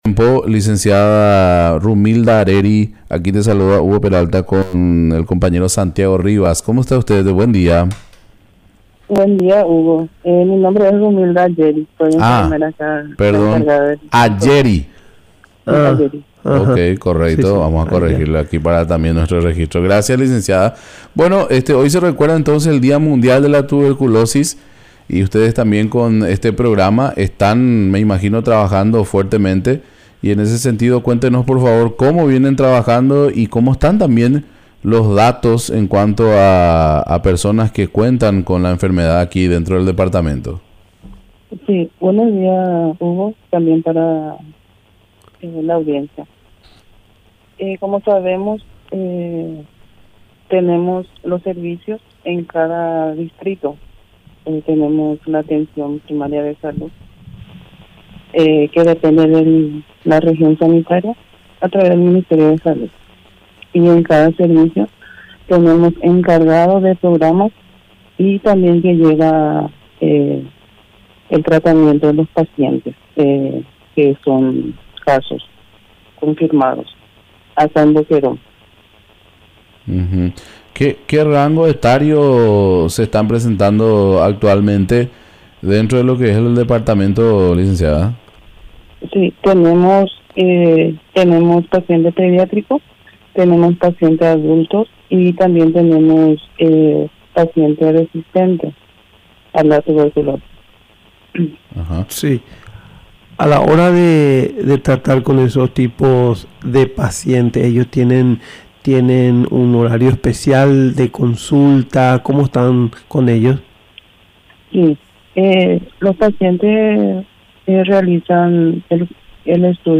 Entrevistas / Matinal 610 Dia mundial de la Tuberculosis.